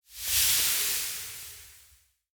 UI_BoneTablet_TransformToSimplified.ogg